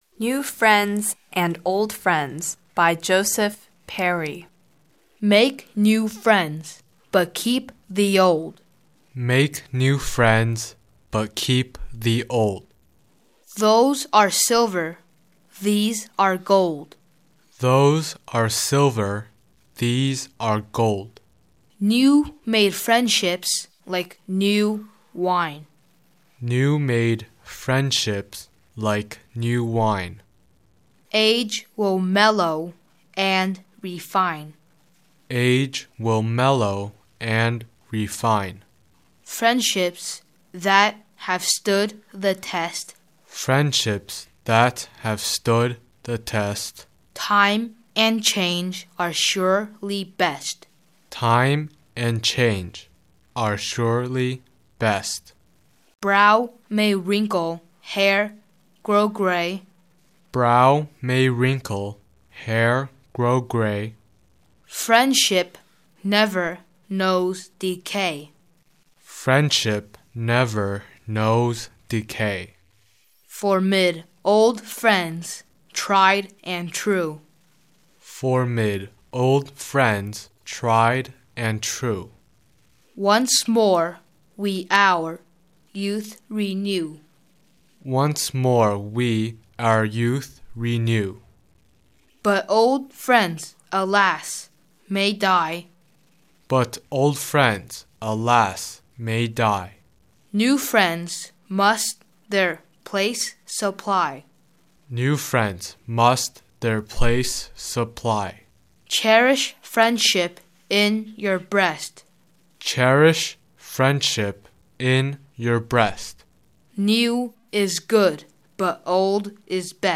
English beautiful reciting poems http